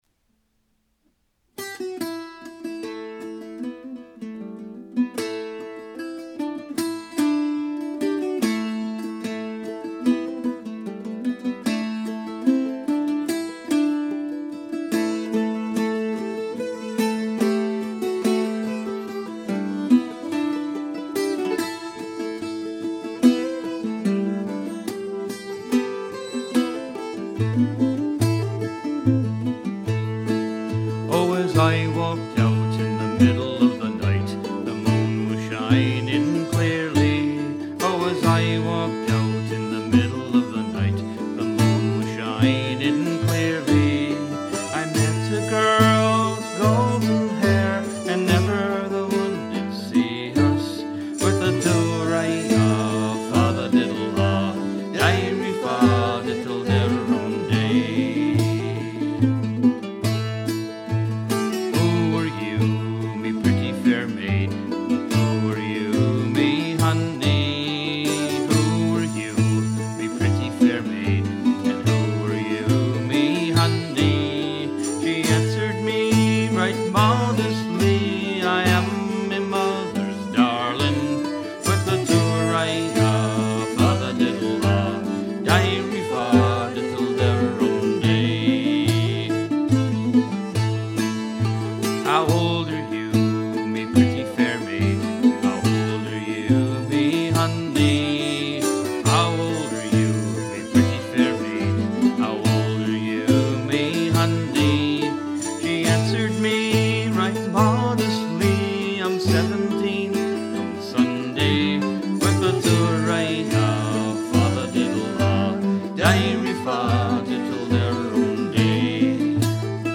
fiddle, tambourine
octave mandolin, voice
cello
flute, guitar, mandolin, voice
" traditional.